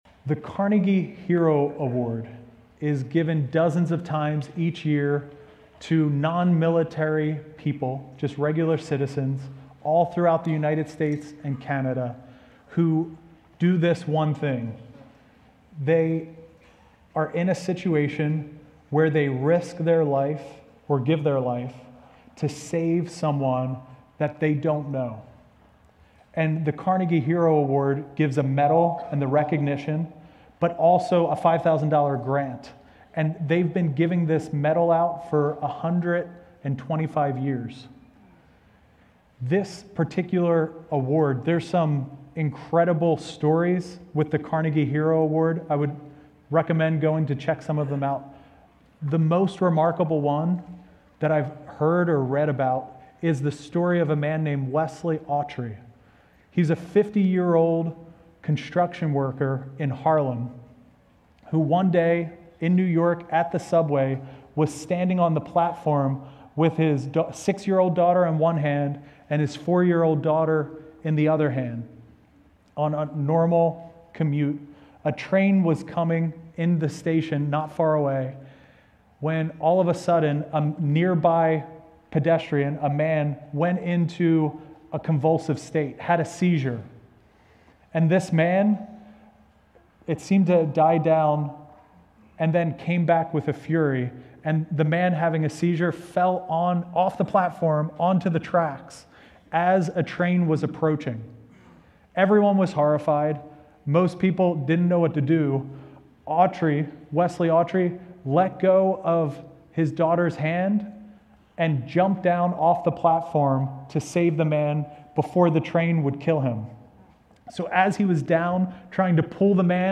Prev Previous Sermon Next Sermon Next Title Step Off the Platform